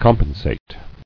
[com·pen·sate]